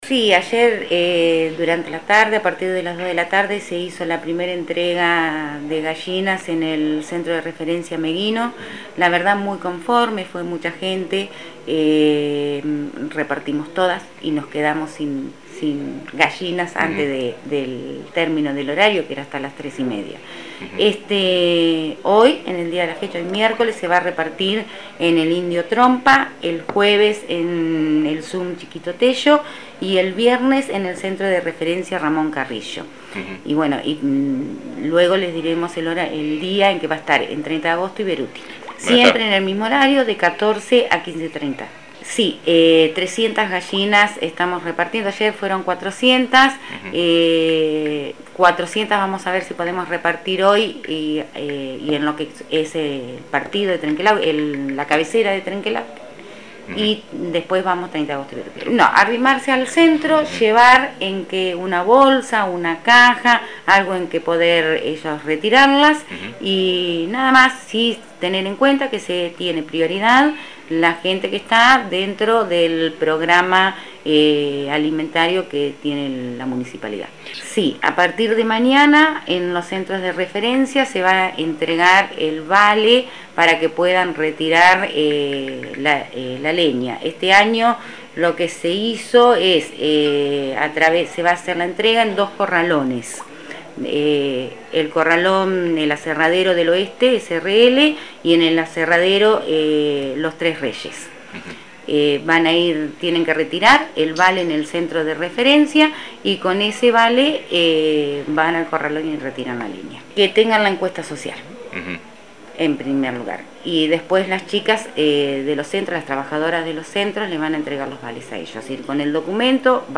Cristina Ferster, Secretaria de Desarrollo Humano de la Municipalidad de Trenque Lauquen habla sobre la entrega de gallinas y leña que está implementando el Municipio.